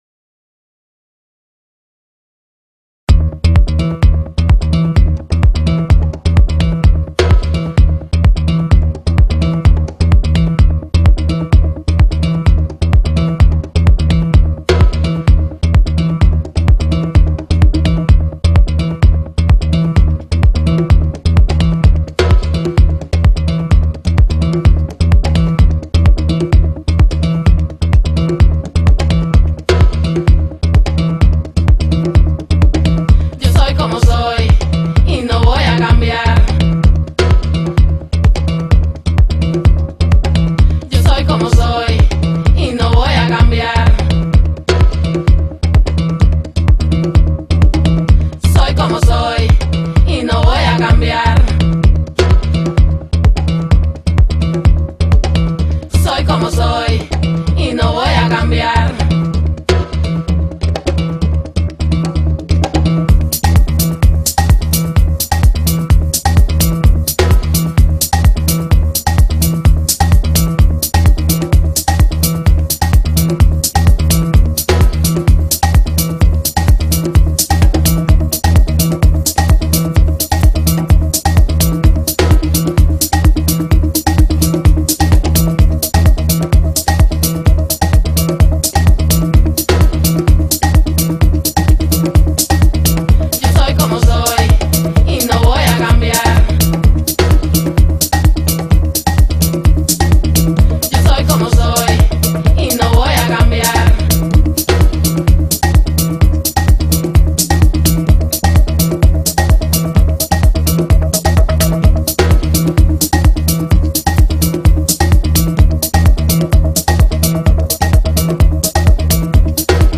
Techno con gusto